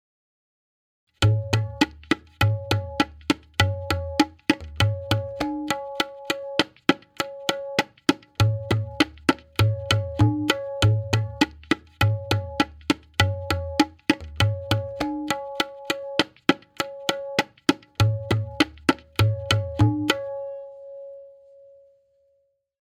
Example 1 (Dohra) – Played Twice